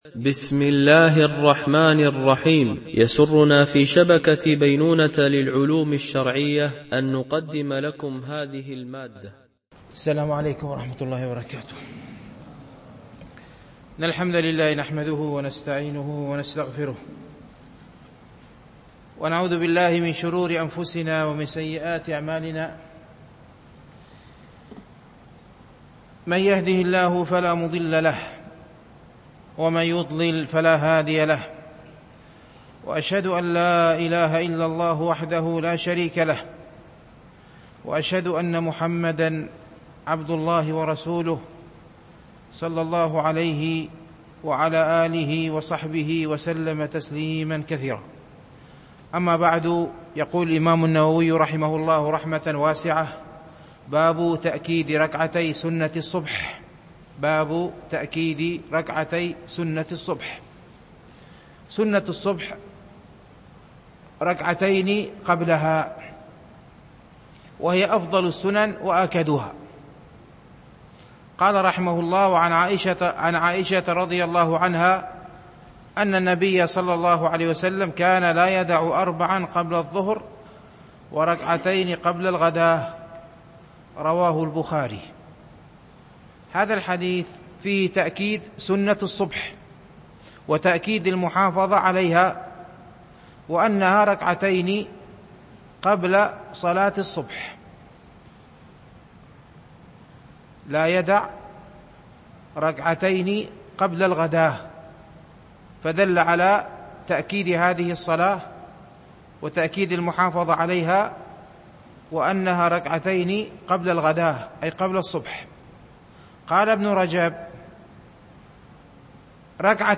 ، الحديث 1107 - 1119 ) الألبوم: شبكة بينونة للعلوم الشرعية التتبع: 255 المدة: 55:49 دقائق (12.81 م.بايت) التنسيق: MP3 Mono 22kHz 32Kbps (CBR)